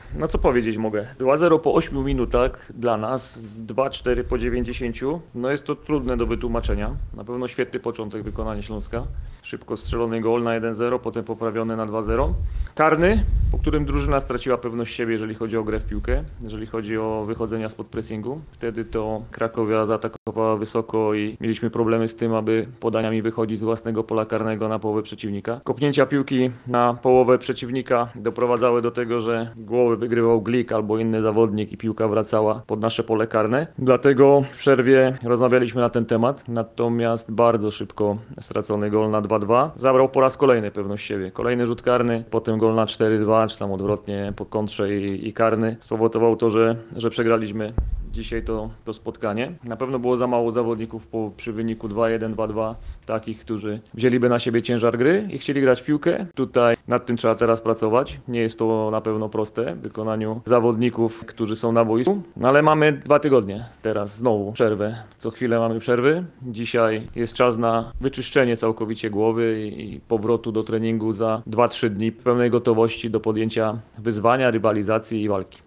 Komentarz po meczu
Występ swojej drużyny podsumował trener Jacek Magiera.